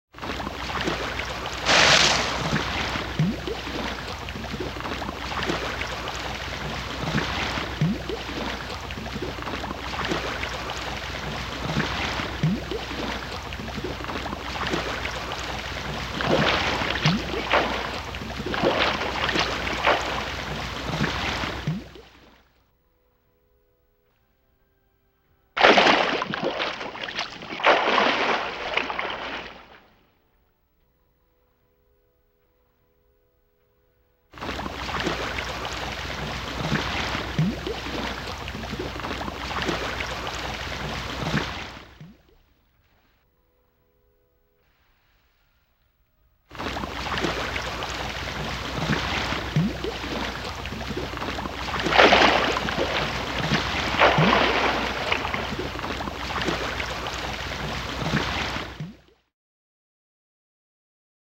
دانلود آهنگ وال 4 از افکت صوتی انسان و موجودات زنده
دانلود صدای وال 4 از ساعد نیوز با لینک مستقیم و کیفیت بالا
جلوه های صوتی